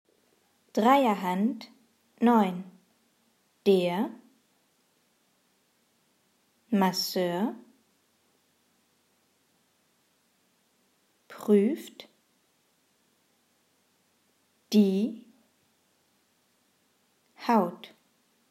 Satz 1 Langsam